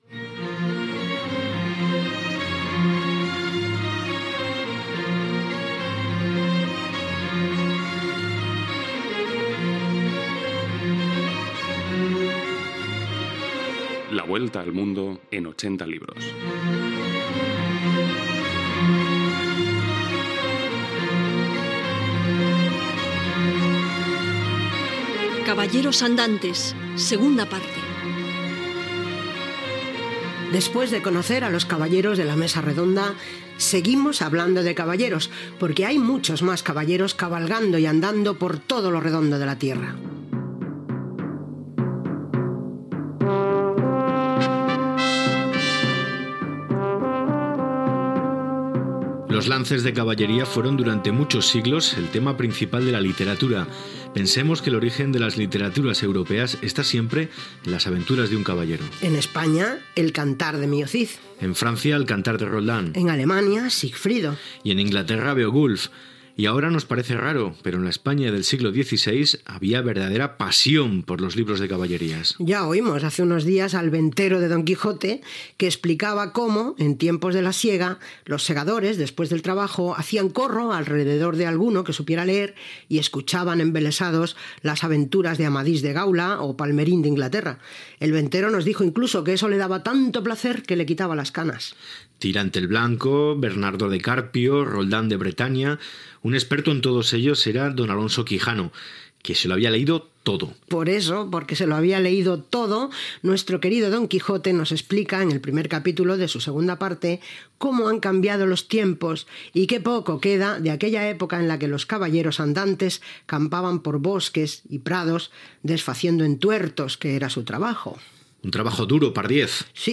Radio Nacional de España Barcelona